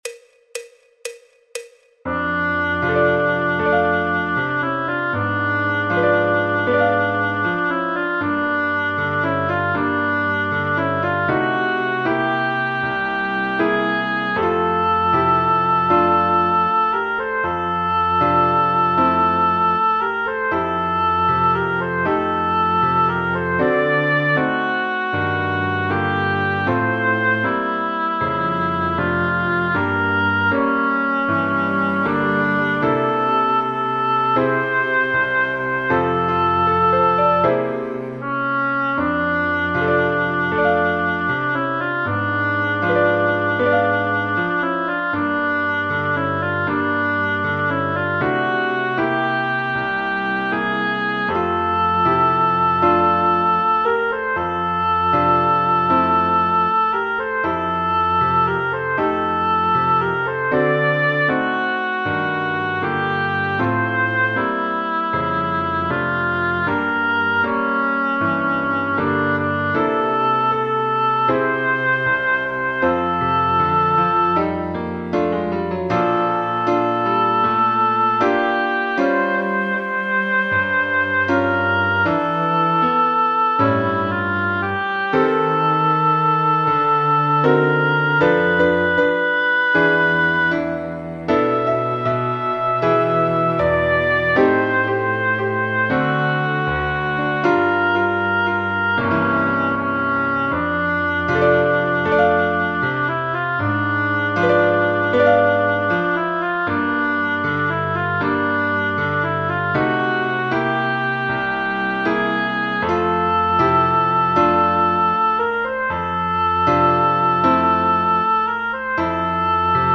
El MIDI tiene la base instrumental de acompañamiento.
Fa Mayor
Jazz, Popular/Tradicional